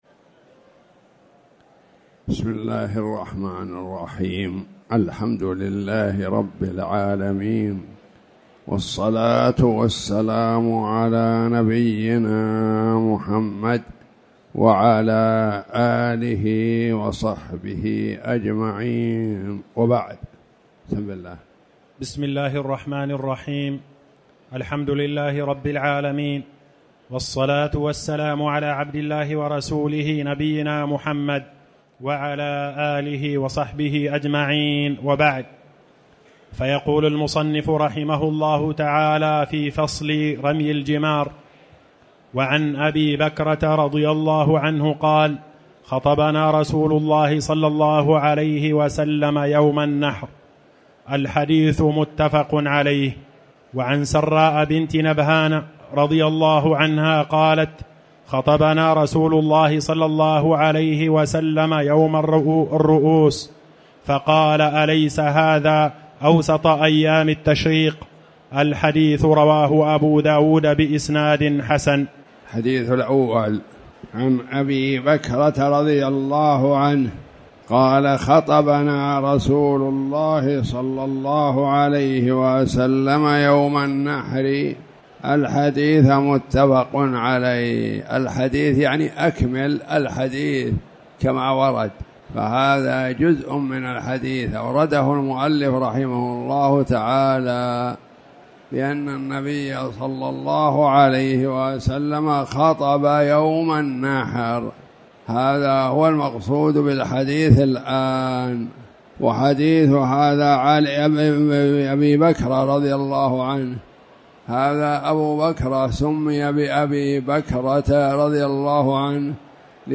تاريخ النشر ١٦ صفر ١٤٣٩ هـ المكان: المسجد الحرام الشيخ